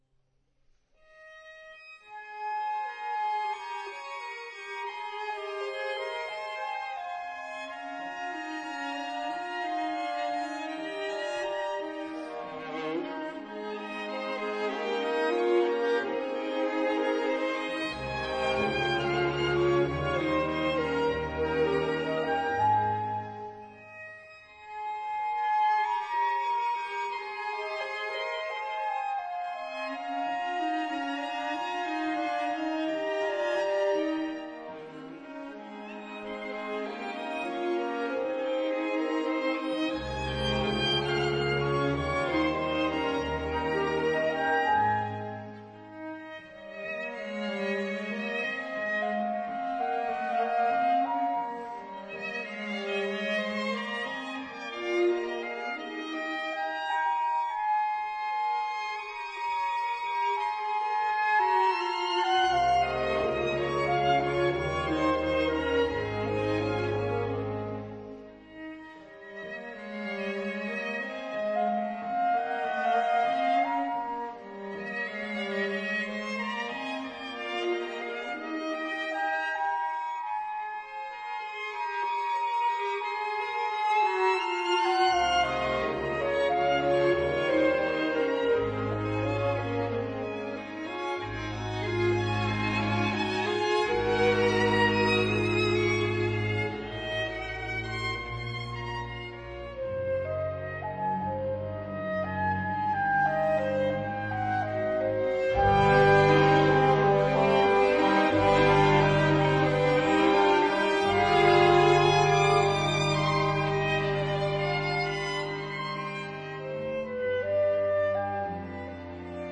單簧管、低音管、法國號、兩把小提琴、中提琴、大提琴和低音提琴。
反而是收縮，像個二重奏、三重奏地親密勾勒。